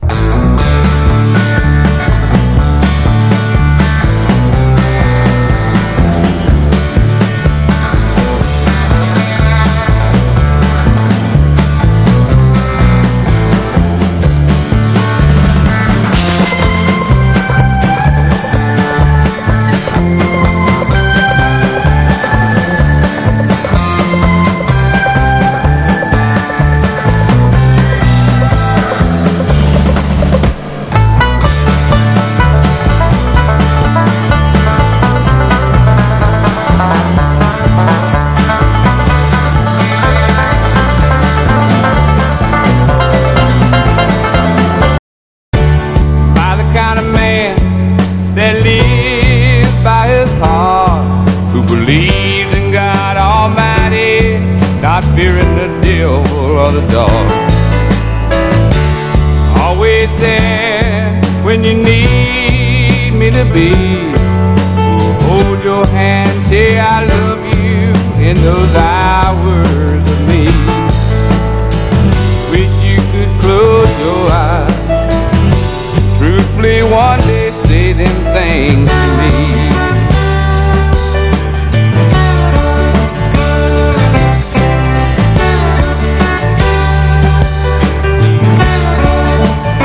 ROCK / OLDIES / ROCKABILLY / ROCK & ROLL
50'Sロカビリーの歴史的コンピ！